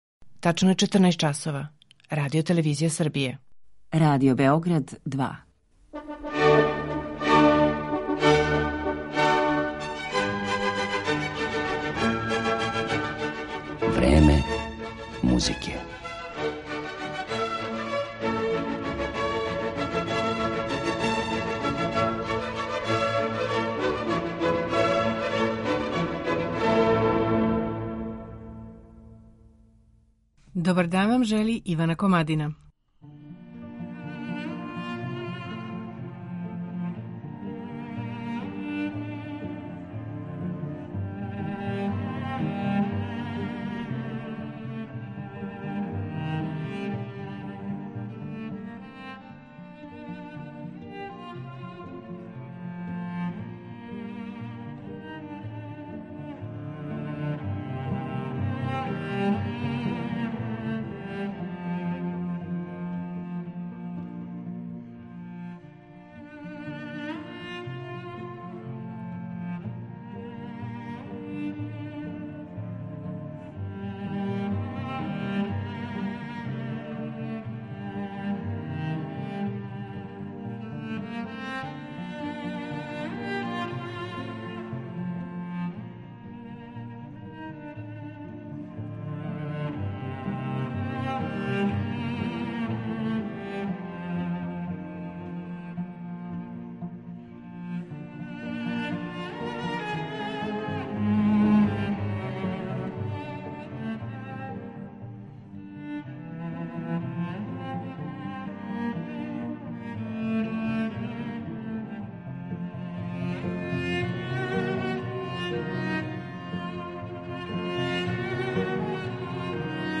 Кијан Солтани, виолончело
Трећег децембра прошле године публику у Kоларчевој задужбини очарао је романтични звук виолончела Kијана Солтанија, младог иранско-аустријског виолончелисте, који је био гост оркестра Београдске филхармоније.